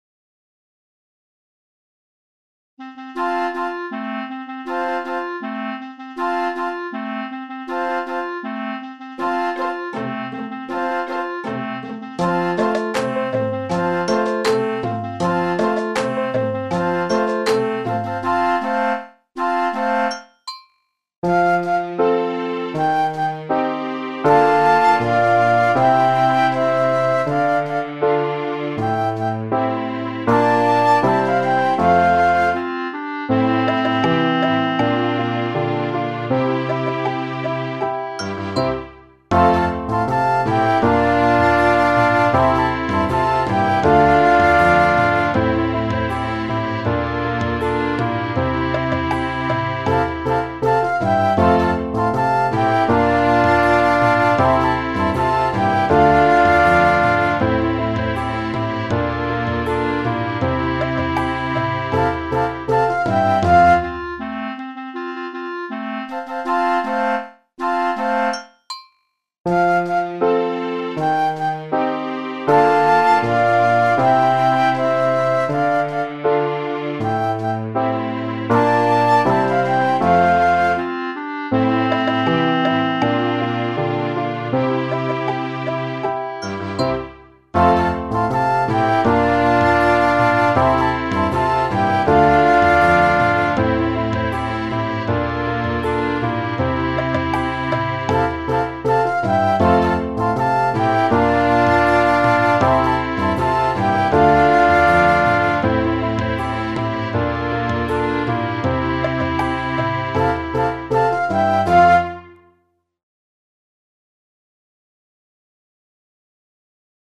BASES INSTRUMENTALS
08-La-fábrica-de-colors-SENSE-VEU.mp3